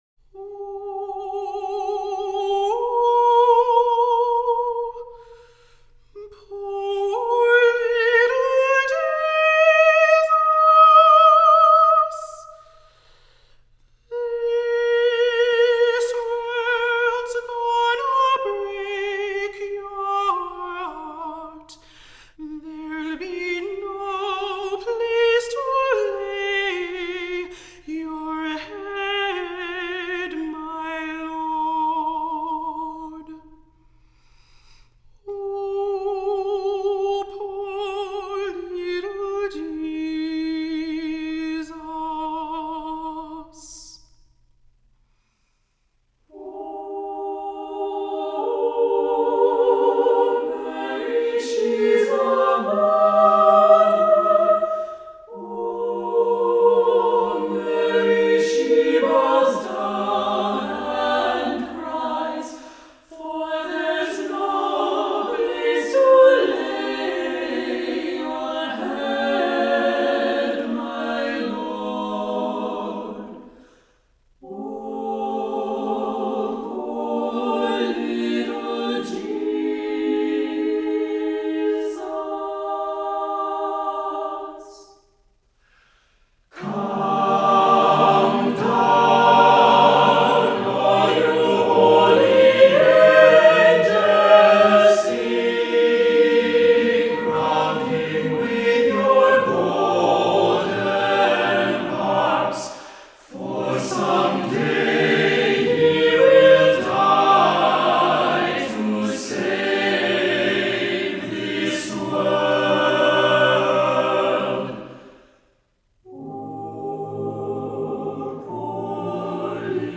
Voicing: SATB, a cappella